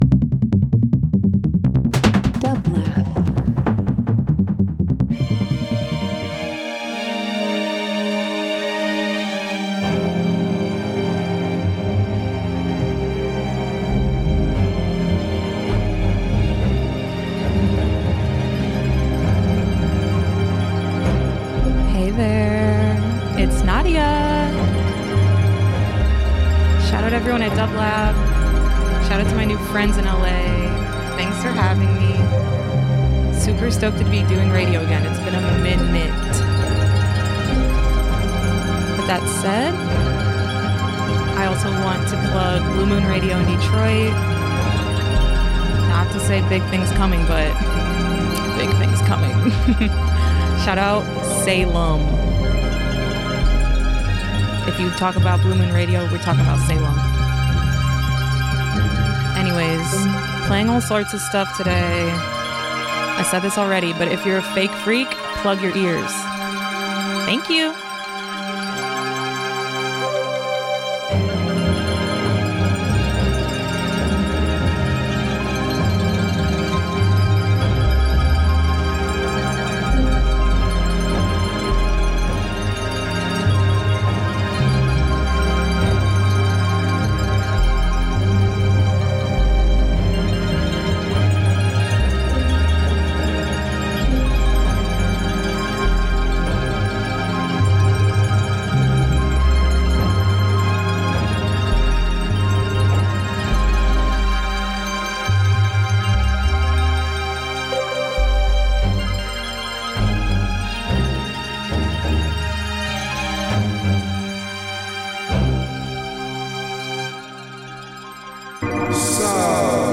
Breaks Electronic Pop Rap